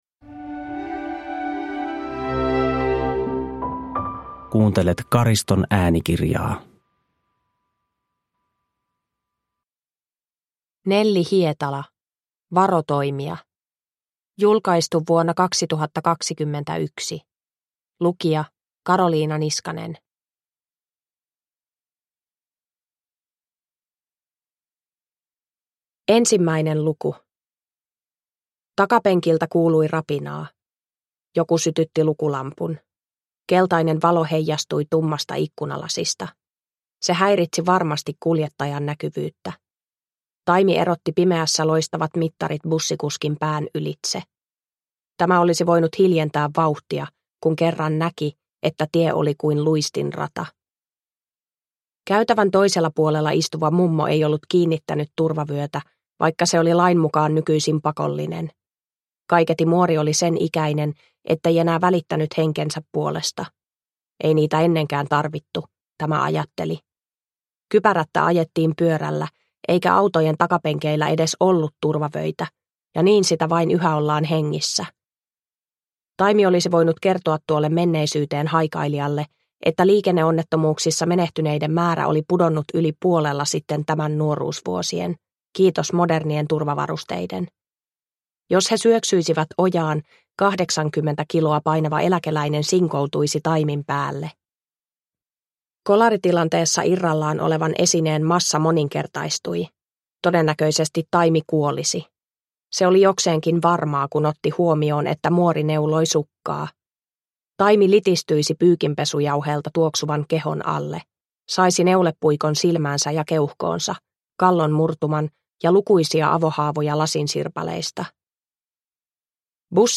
Varotoimia – Ljudbok – Laddas ner